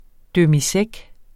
Udtale [ dəmiˈsεg ]